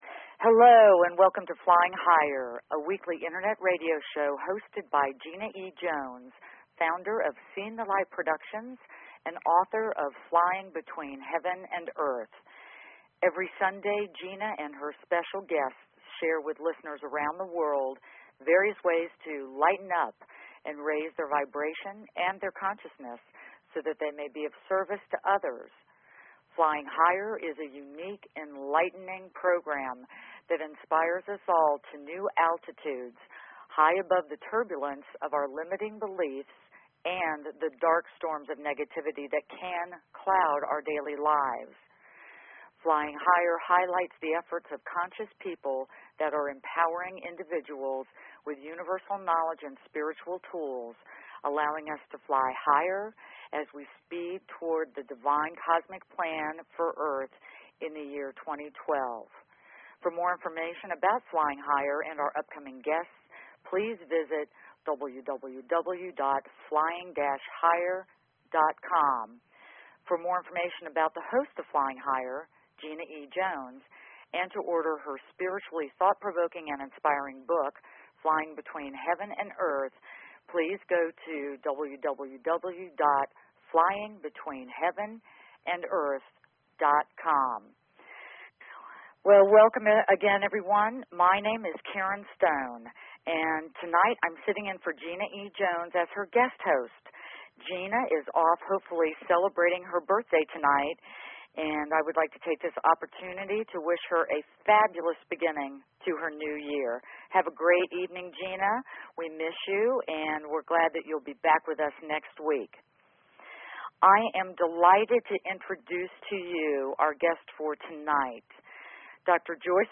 Talk Show Episode, Audio Podcast, Flying_Higher and Courtesy of BBS Radio on , show guests , about , categorized as